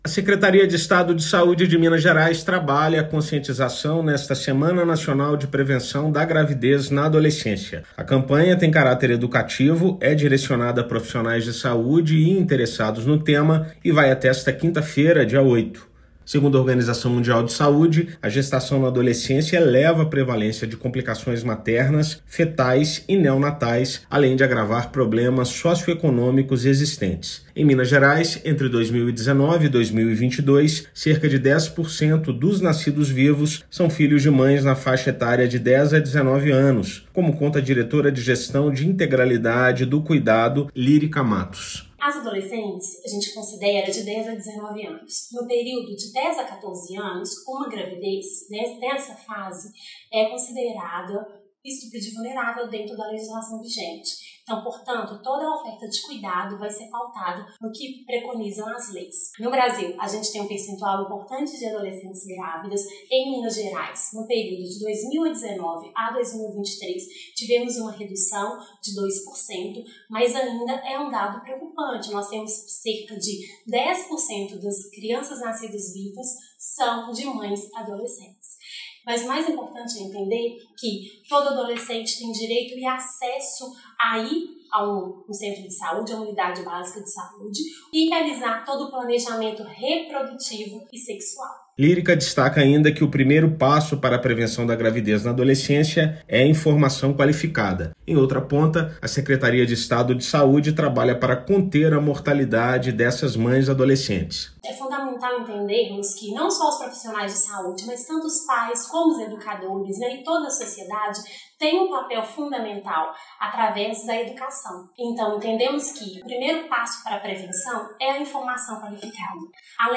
Educação sexual, acolhimento e acompanhamento são as melhores medidas para evitar complicações e óbitos materno-infantis. Ouça matéria de rádio.